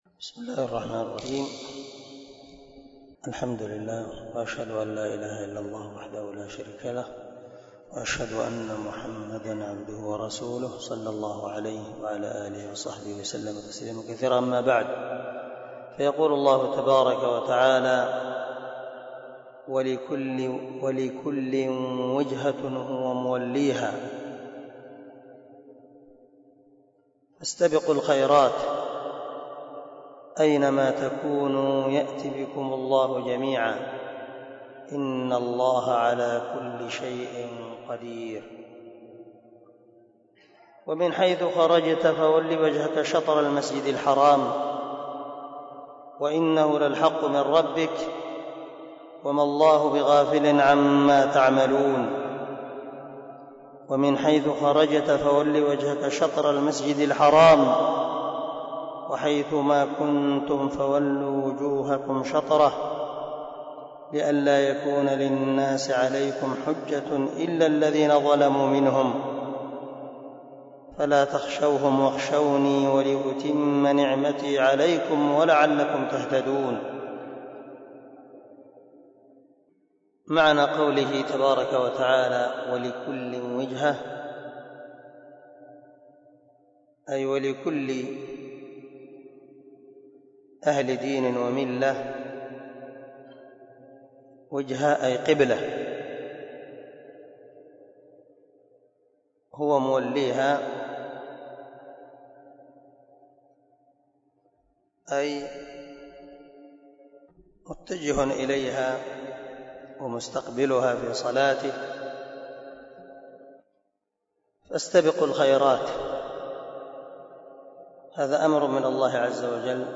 063الدرس 53 تفسير آية ( 148 – 150 ) من سورة البقرة من تفسير القران الكريم مع قراءة لتفسير السعدي
دار الحديث- المَحاوِلة- الصبيحة.